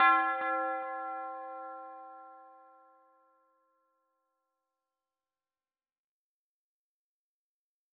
Waka - CHURCH BELL.wav